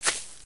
sounds / material / human / step / grass01gr.ogg
grass01gr.ogg